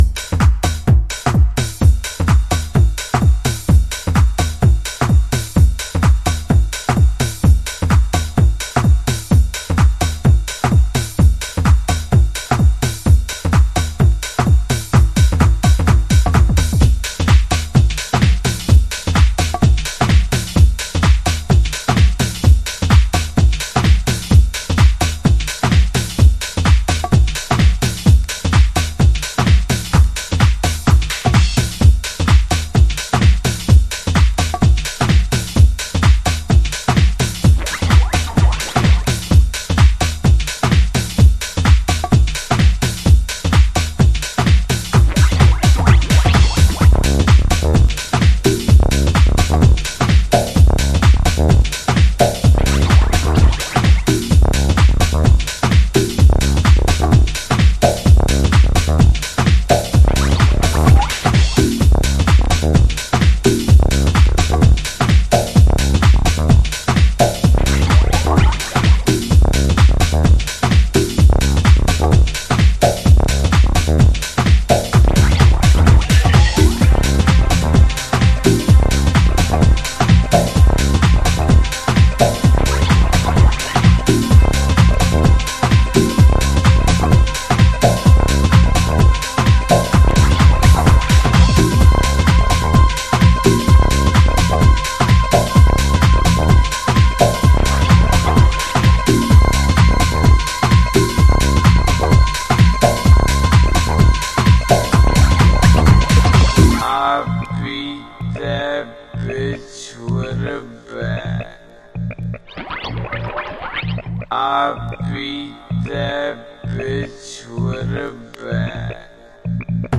NJのゲトースタイル。